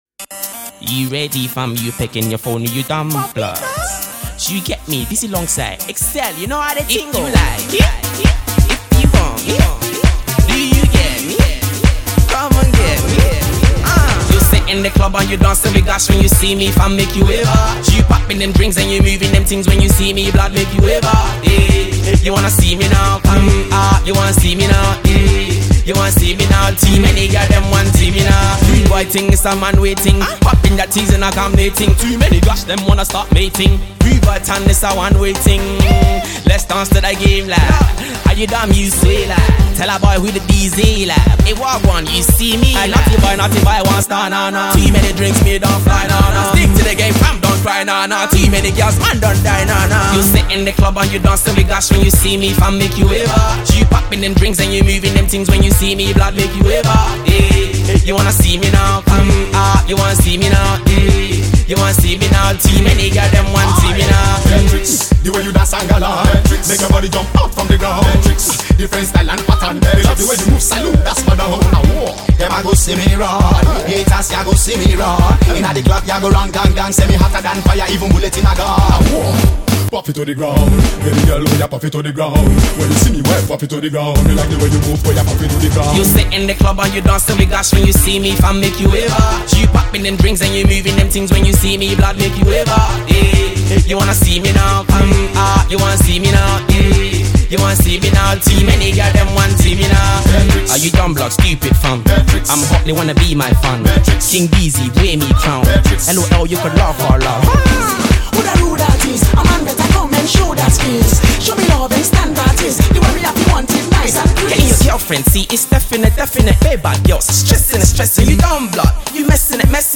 a Nigerian rapper/singer based in the UK